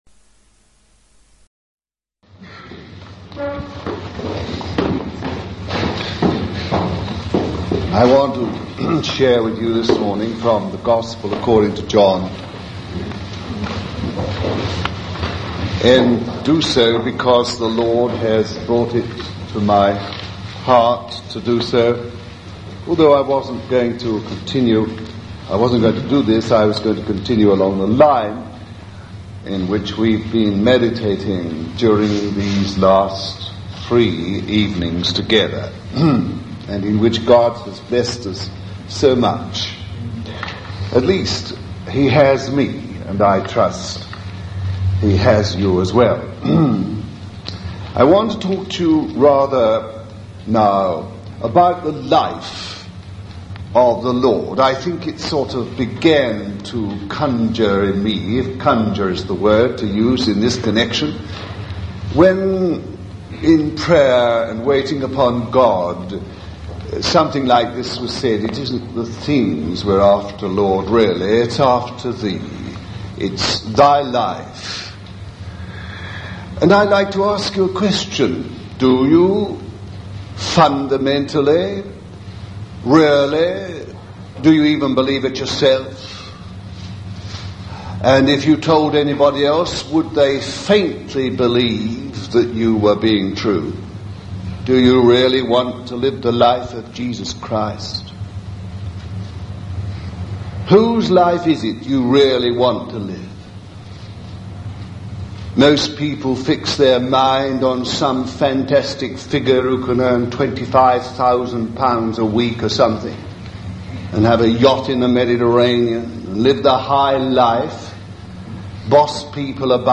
Do you want this life - Reading May 7-8, 1977